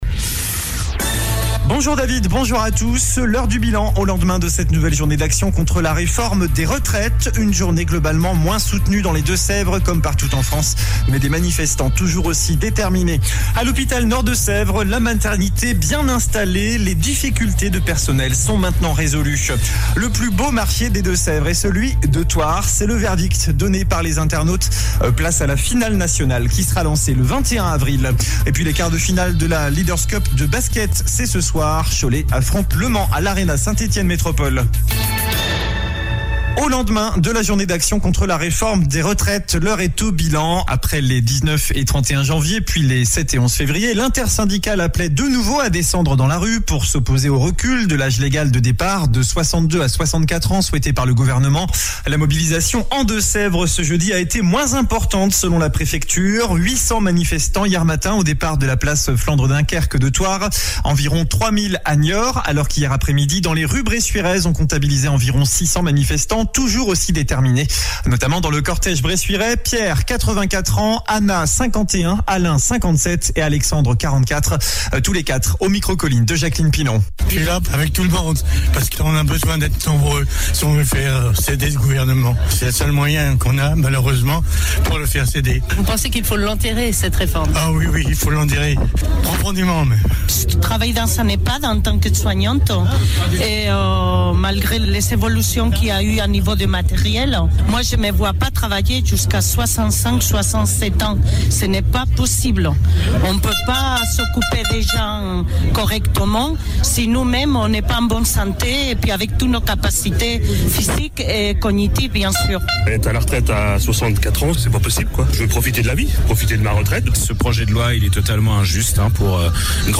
JOURNAL DU VENDREDI 17 FEVRIER ( MIDI )